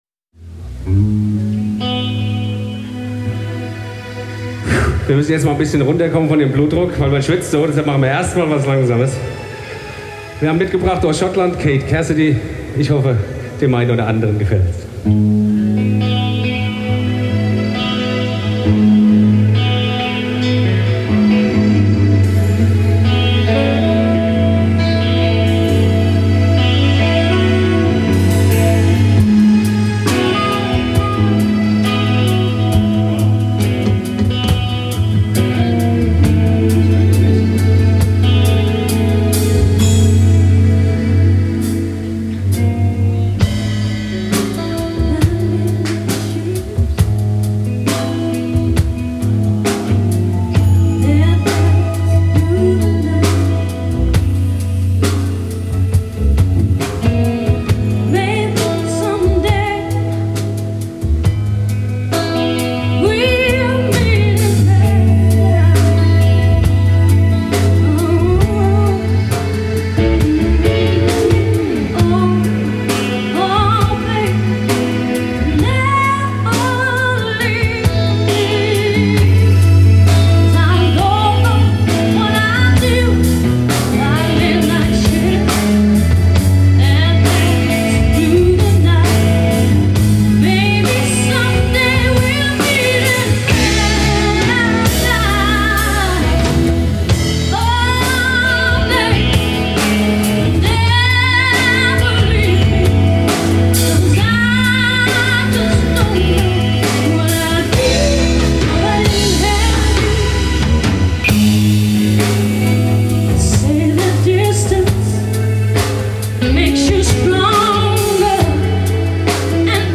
We also played a slower jam where I had my problems
Plus you can hear my lazy German announcements!
I think there's not much echo on this recording though!